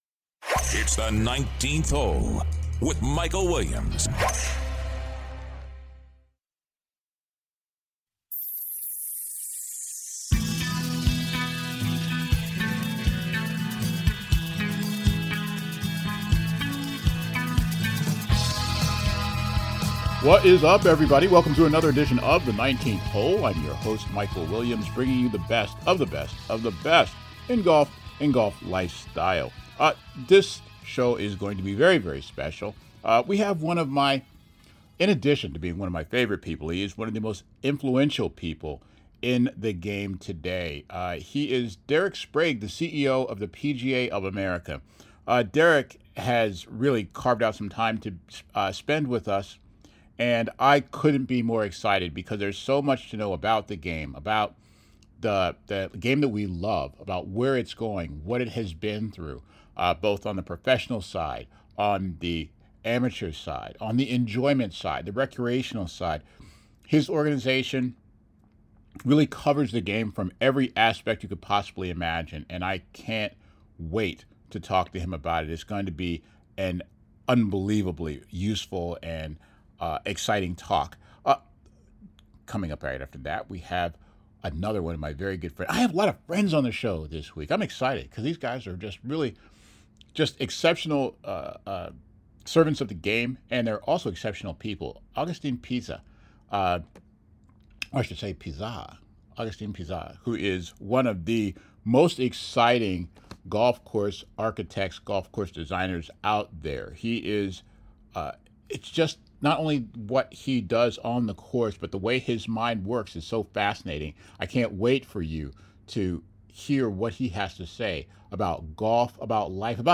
Episode 220: Exclusive! Our One-on-One Interview